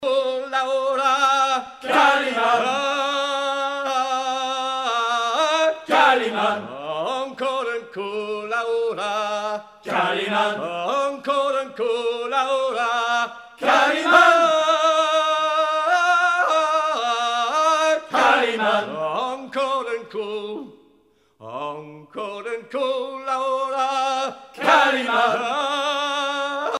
circonstance : maritimes
Genre laisse
Pièce musicale éditée